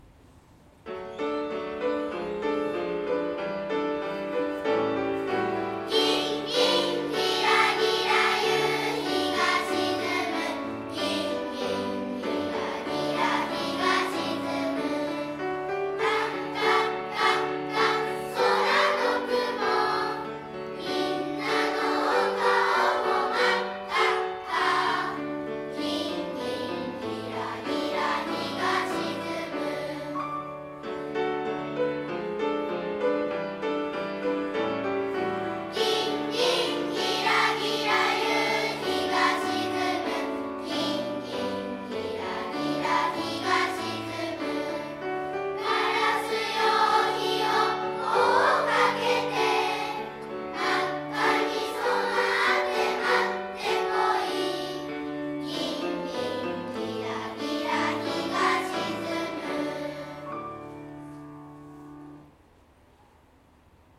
令和５年度　音楽会
斉唱 「夕やけ小やけ」 「